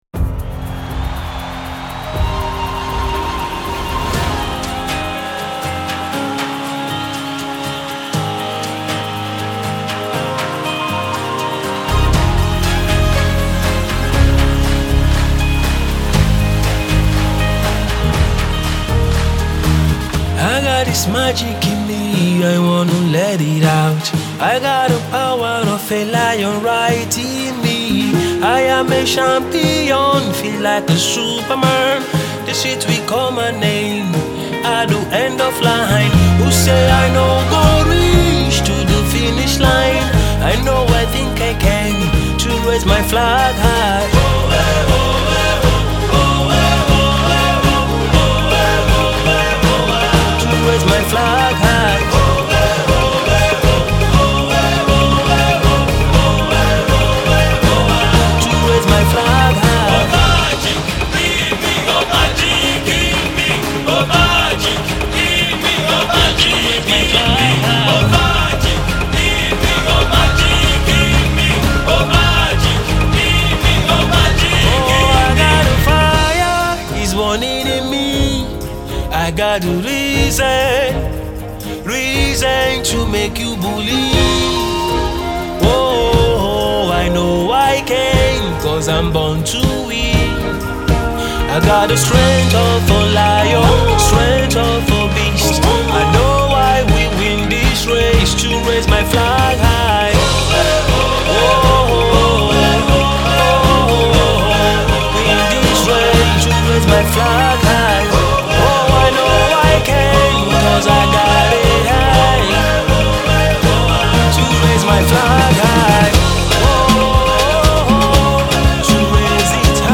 Alternative Soul
an inspirational tune